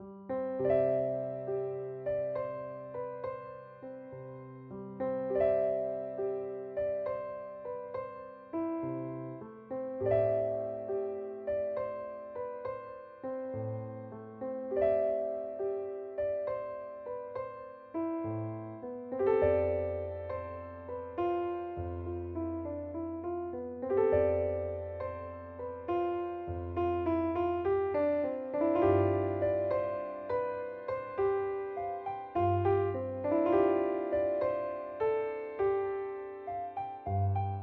夜晚花园里蛐蛐的叫声
描述：在自己的花园里听见蛐蛐叫得很欢，于是录了下来
标签： 大自然 蛐蛐 手机录制
声道立体声